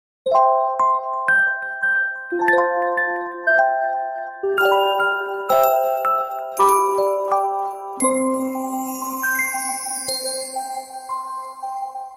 جلوه های صوتی